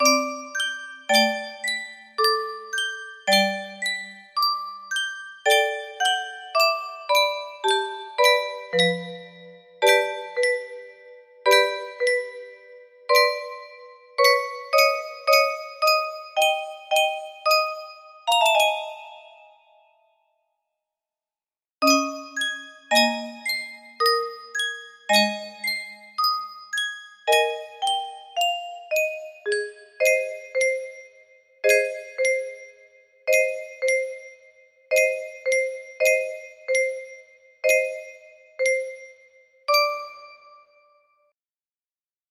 #0d69ac music box melody
Full range 60